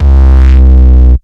Distort Kick 1.wav